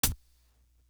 Stuck To You Hat.wav